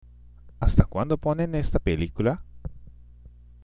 （アスタクアンド　ポネン　ネスタ　ペリクラ？）